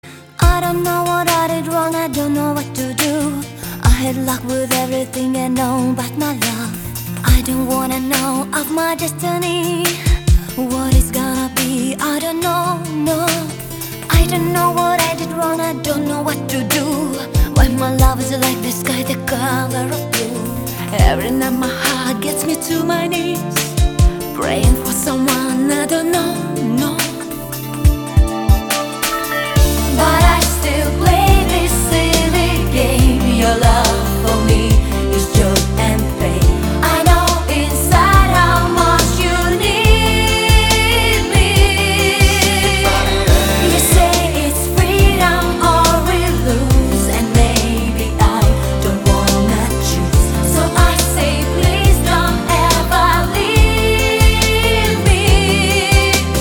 • Качество: 192, Stereo
женский вокал
романтичные